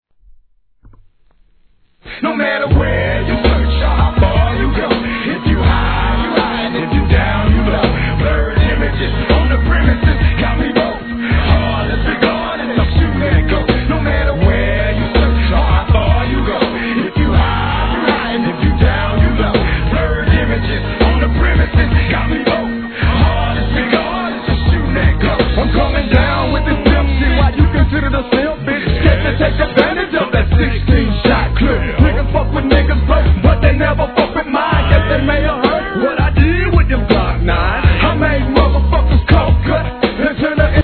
G-RAP/WEST COAST/SOUTH
「ポワン ポワン」と浮遊感ある上音が特徴的なフックと何処となくメロディアスで独創的な絶妙な空間を作り出した中毒系一曲